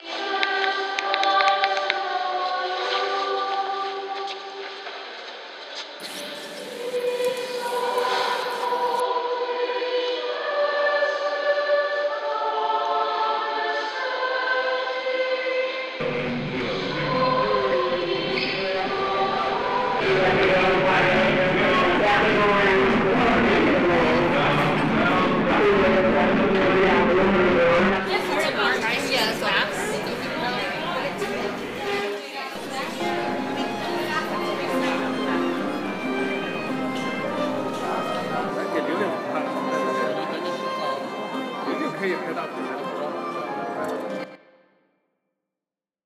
Listened to birds chirping, people conversing, and performers performing … it all adds to the fabric of a city. Most sounds blend into each other, but at times, you will hear something that captures your attention. Here is a rough sampling of sounds, not yet a choreographed compilation, but rather a taste of what has captured my attention and expanded my experience in Milan and other cities abroad.
study-abroad-textures2.m4a